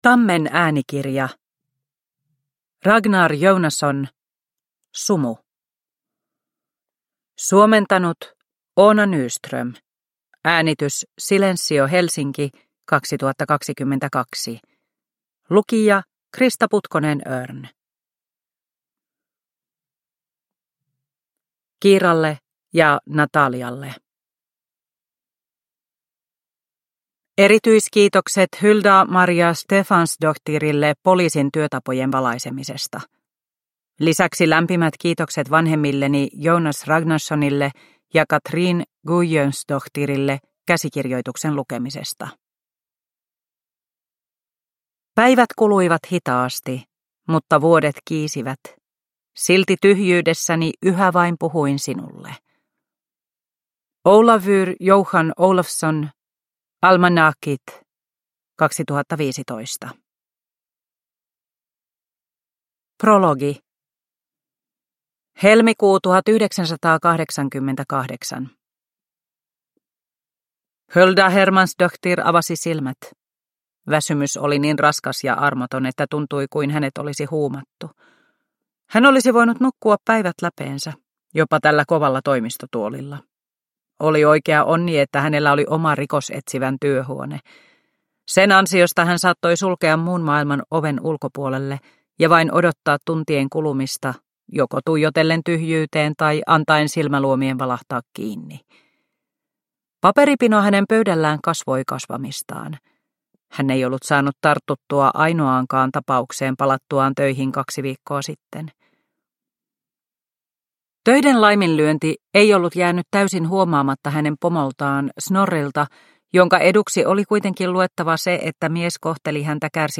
Sumu – Ljudbok – Laddas ner